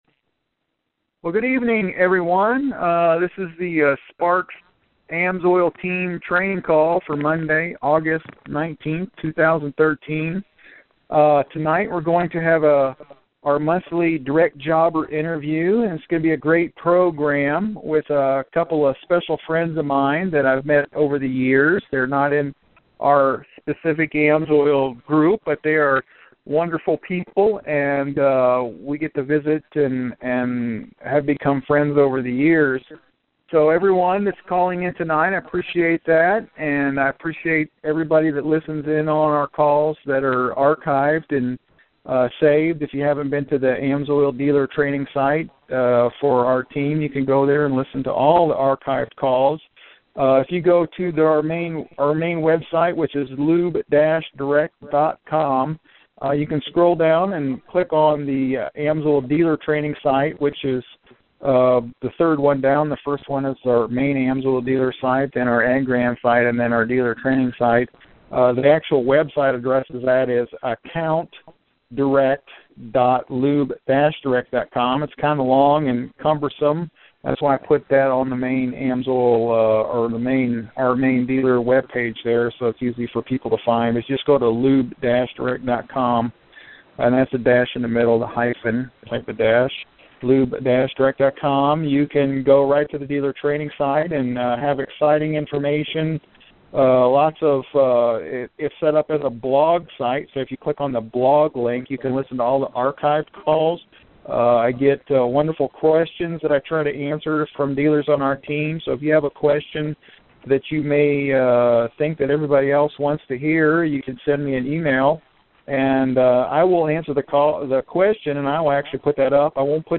We interview top AMSOIL Dealers and talk about building a success business, how they have done it.